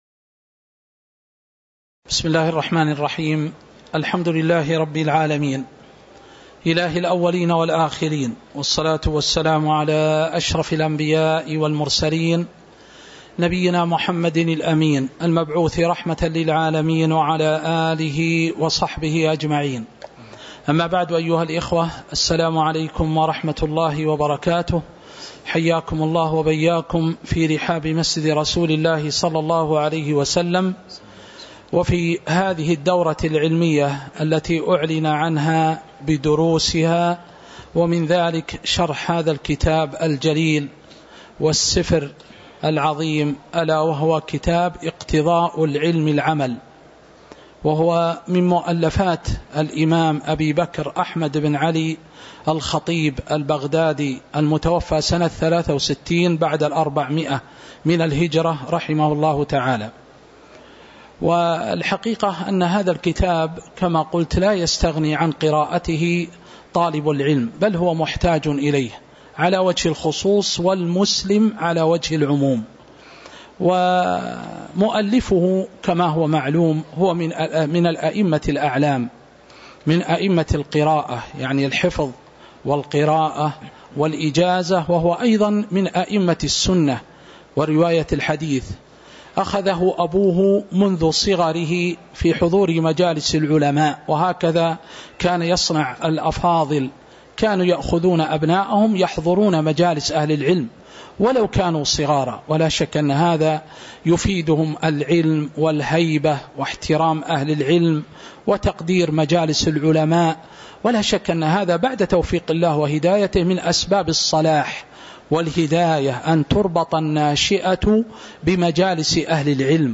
تاريخ النشر ٢٠ جمادى الآخرة ١٤٤٦ هـ المكان: المسجد النبوي الشيخ